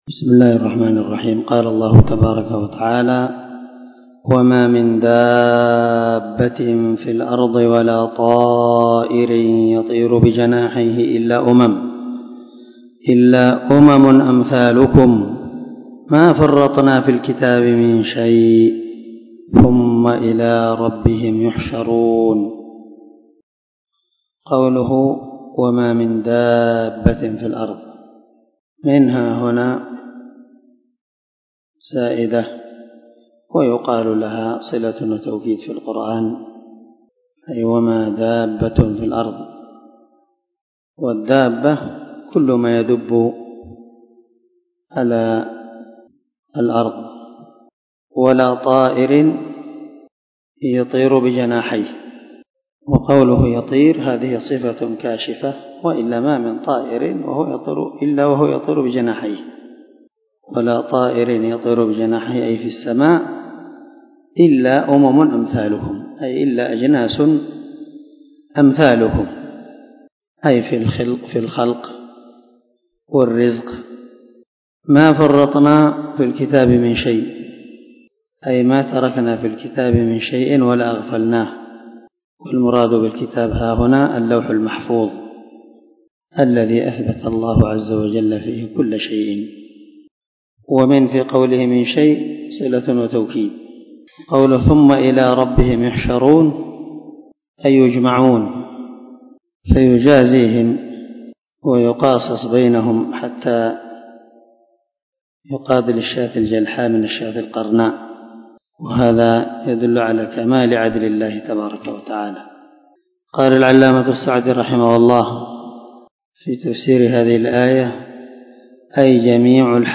402الدرس 10 تفسير آية ( 38 – 41 ) من سورة الأنعام من تفسير القران الكريم مع قراءة لتفسير السعدي